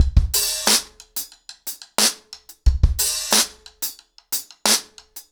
ROOTS-90BPM.39.wav